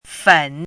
chinese-voice - 汉字语音库
fen3.mp3